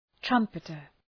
{‘trʌmpıtər}